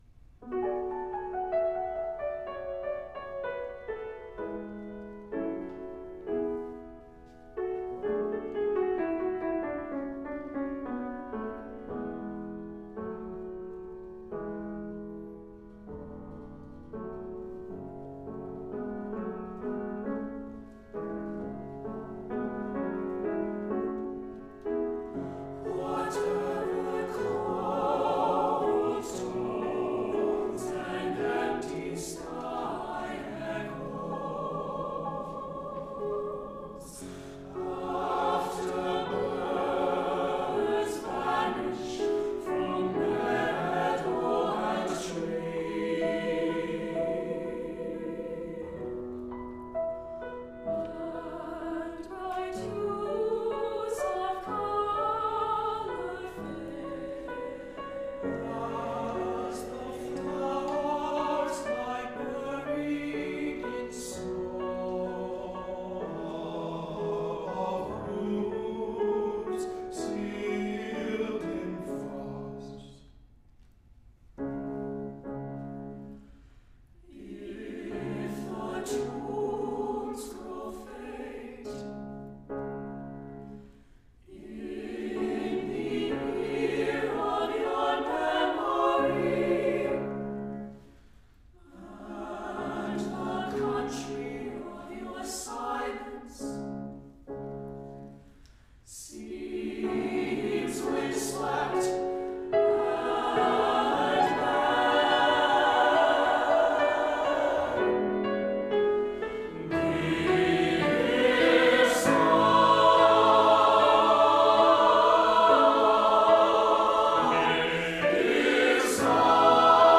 for SATB chorus (div.) and piano